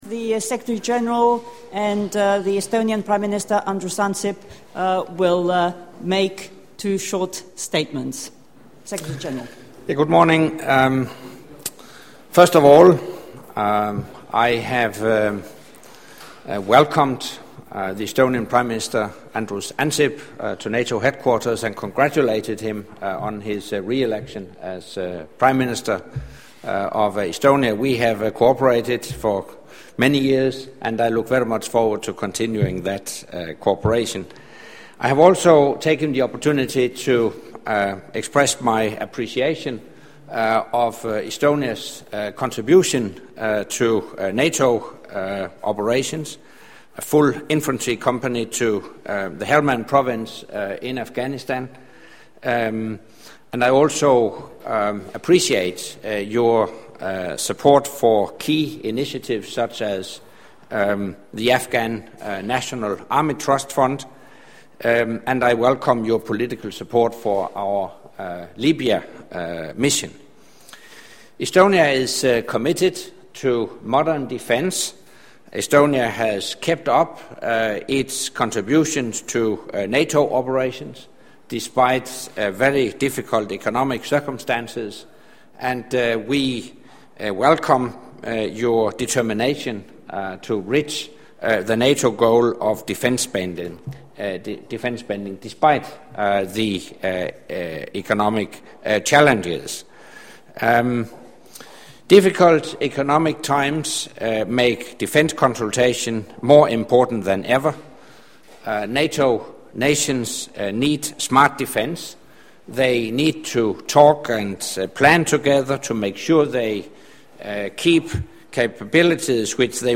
Joint press briefing by NATO Secretary General, Anders Fogh Rasmussen and the Prime Minister of Estonia, Mr. Andrus Ansip